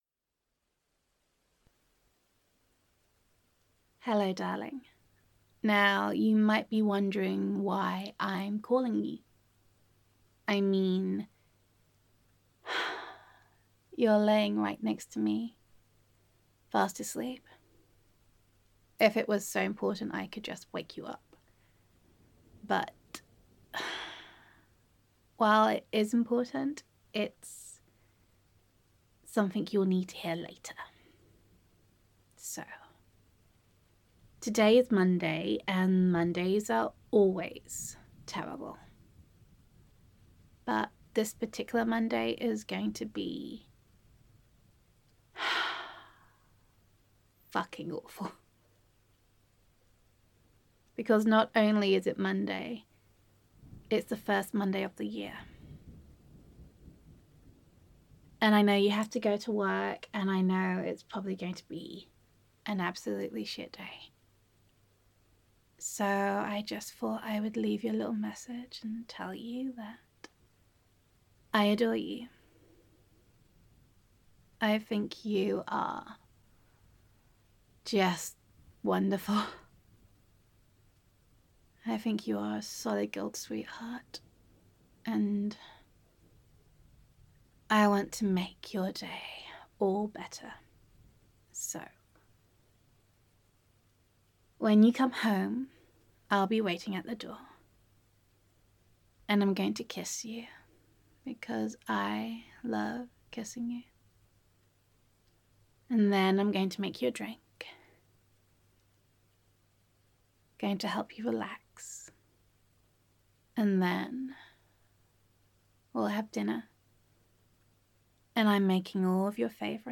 [F4A] The First Monday of the Year [Making Your Bad Day Better][Kicking Monday’s Arse][Gender Neutral][Loving Girlfriend Voicemail]